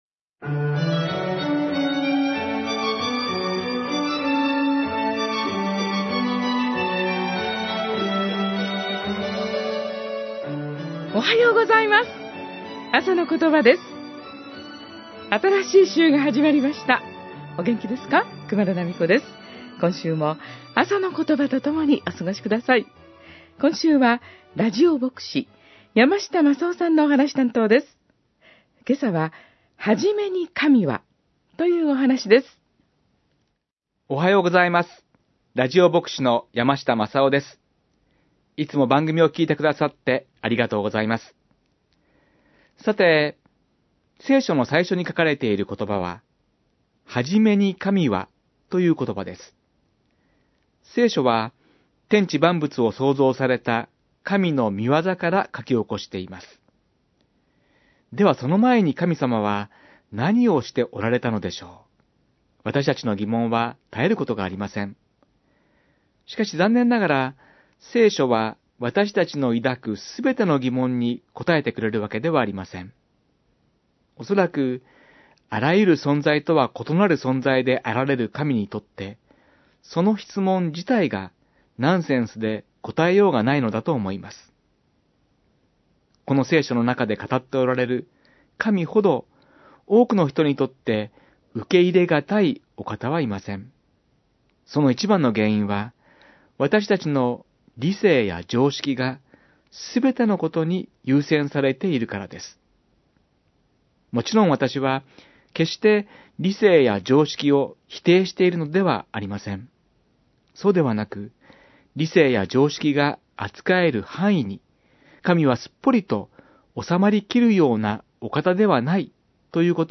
ラジオ牧師